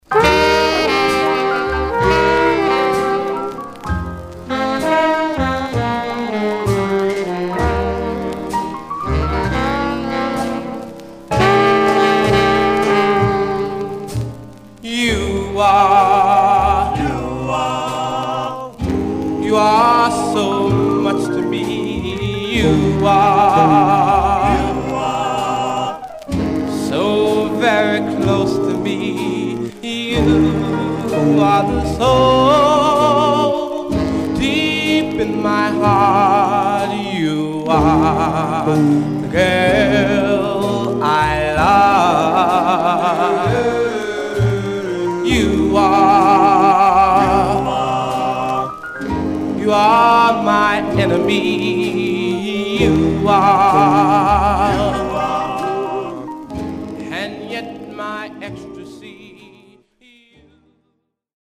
Alternate Version (Rainbow Label) Condition: M- SOL
Stereo/mono Mono
Male Black Groups